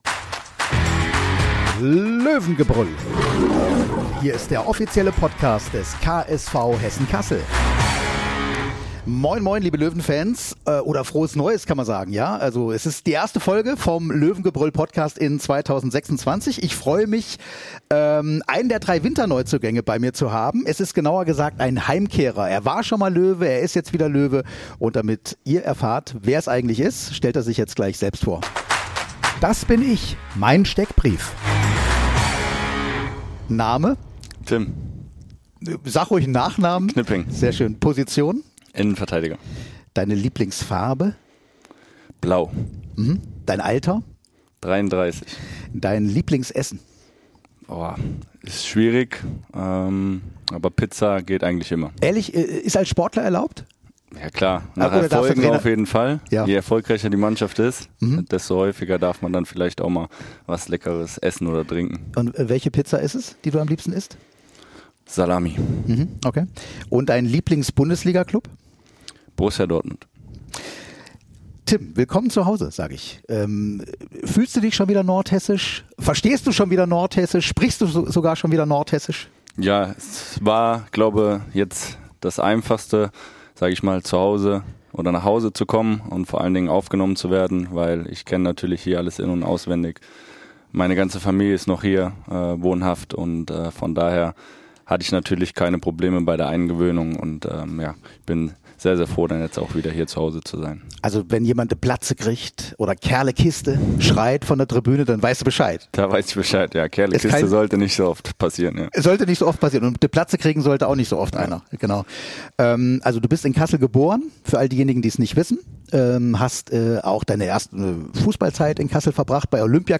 Wie immer kommen auch zahlreiche Wegbegleiter des Innenverteidigers zu Wort, wie BvB-Keeper Alexander Meyer und Stürmer Stefan Kutschke von Dynamo Dresden.